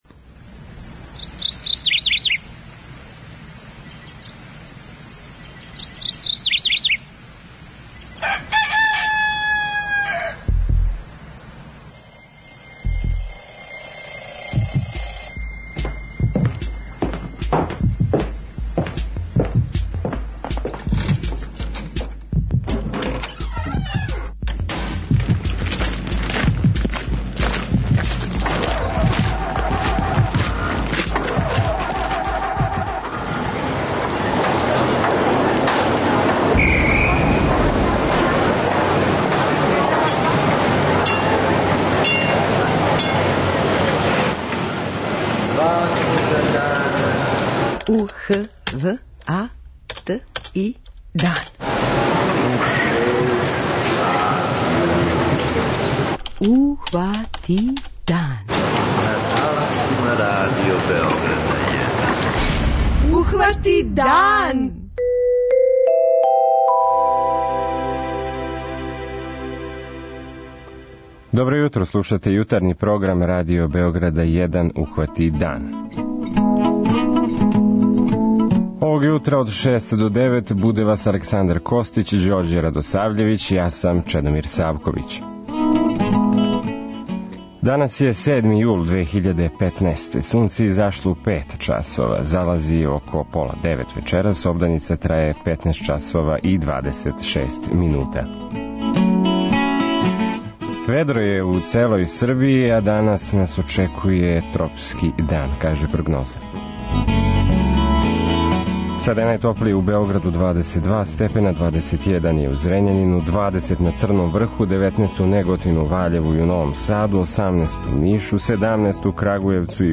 За наш програм говоре његови чланови.
преузми : 43.16 MB Ухвати дан Autor: Група аутора Јутарњи програм Радио Београда 1!